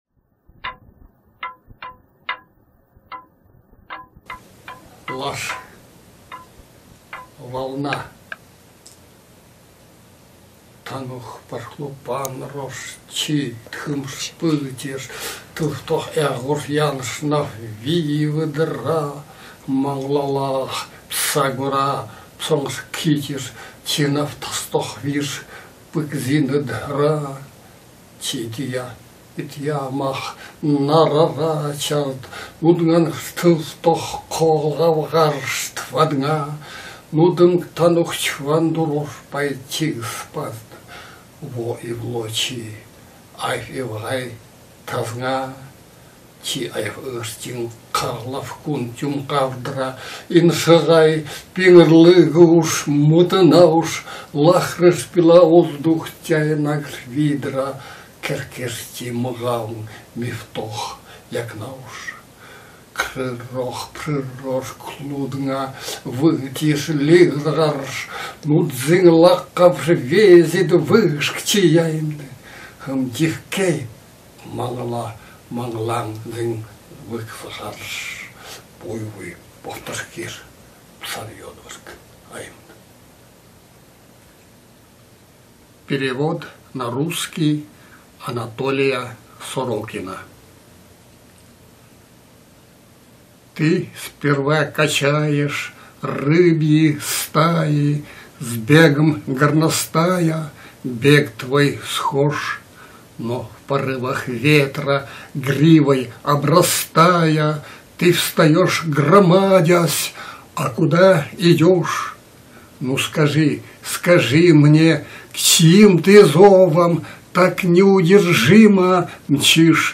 Санги.mp3 — Стихи на нивхском языке в исполнении автора, В.М. Санги